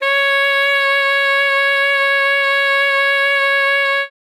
42c-sax09-c#5.wav